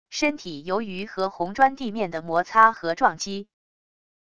身体由于和红砖地面的摩擦和撞击wav音频